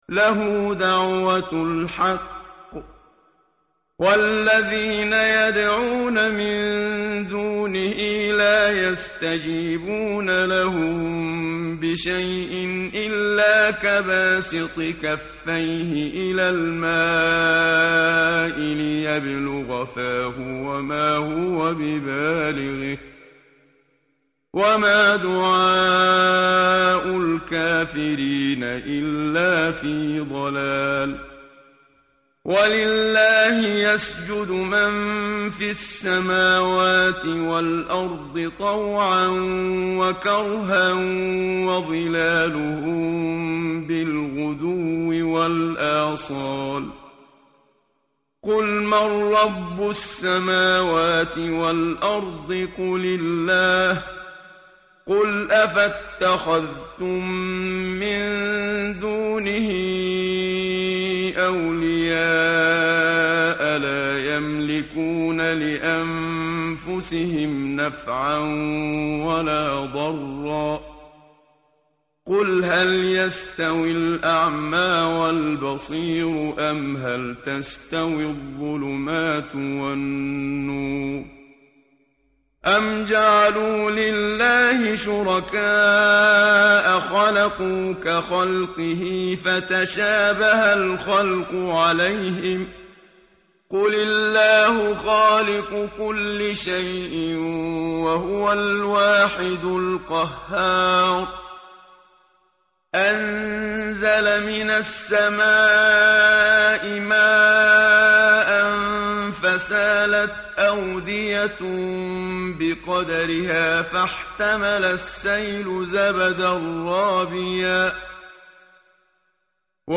متن قرآن همراه باتلاوت قرآن و ترجمه
tartil_menshavi_page_251.mp3